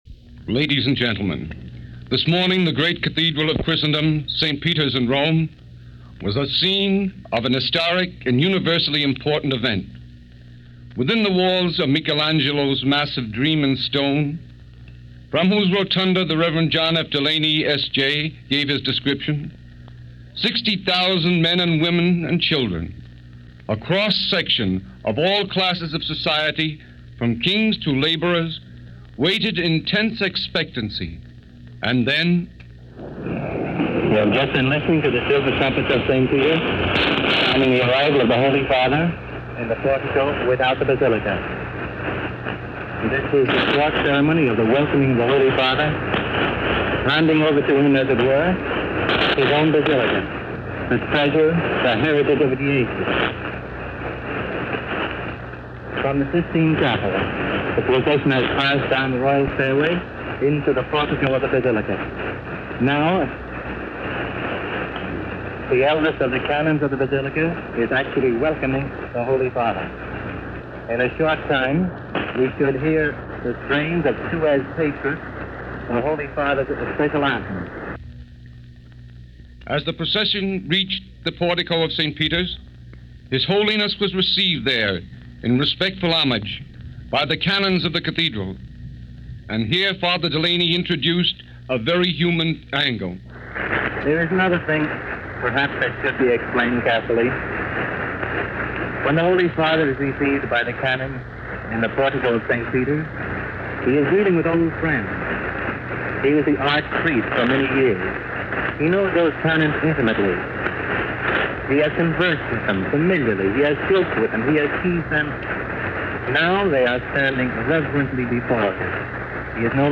Here is the recording of the Coronation of Pope Pius XII as it was broadcast by Mutual via Vatican Radio ‘s English transmission in Rome on March 12, 1939. The sound fades in and out, and is hard to understand at time – due to the nature of Shortwave radio at the time.